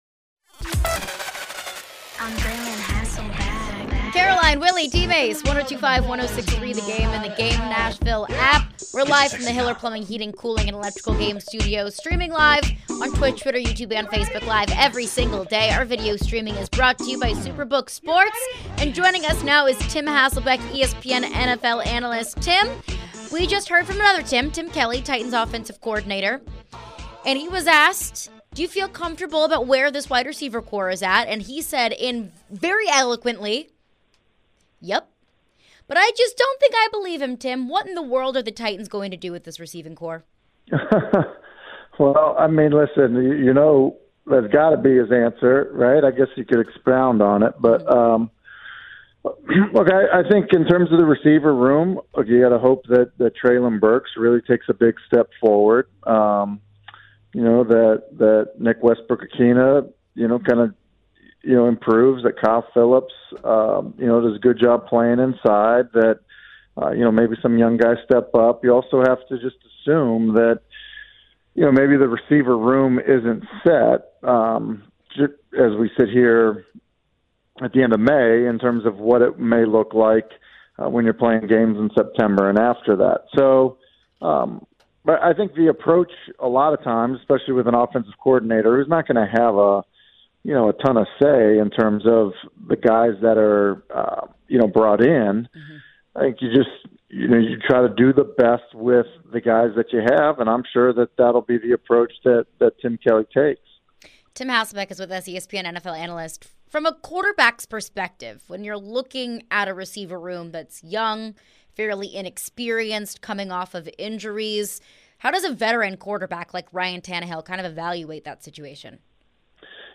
Tim Hasselbeck Interview (5-23-23)